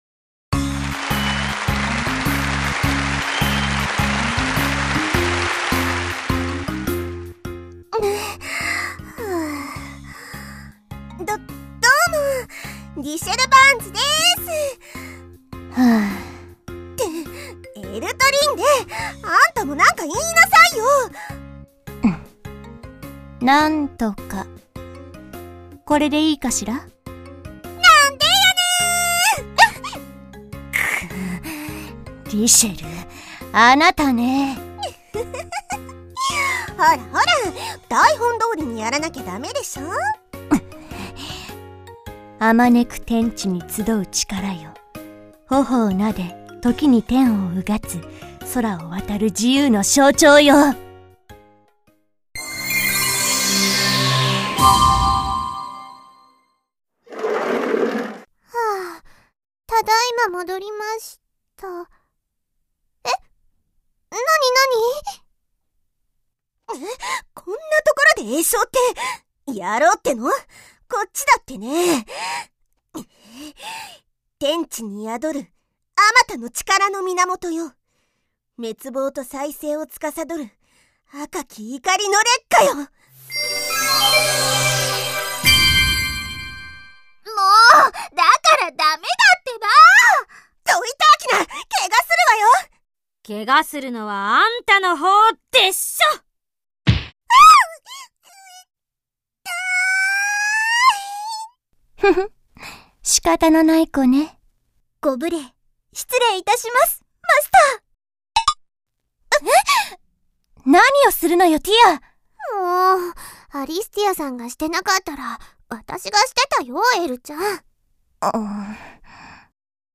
WEBドラマ 第2回を公開！！